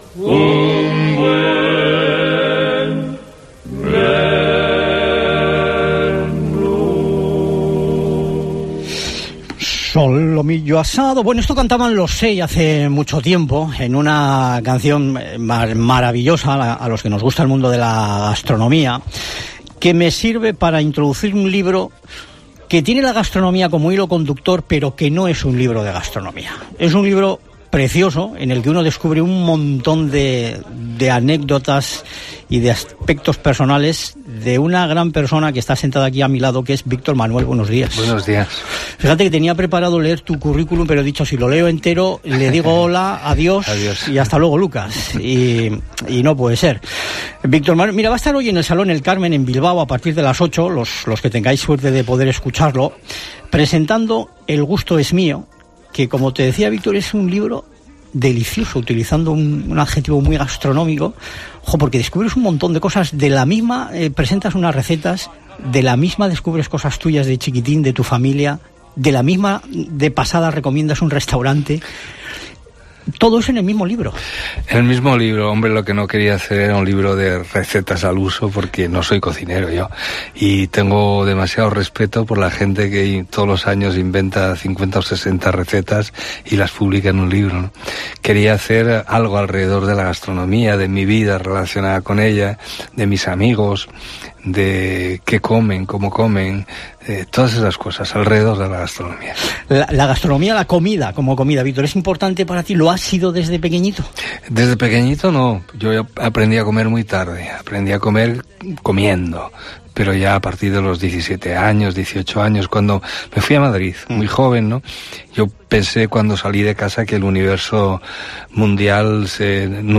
"No quería hacer un libro de recetas al uso porque no soy cocinero, quería hacer algo alrededor de la gastronomía", ha contado en la entrevista en COPE Euskadi.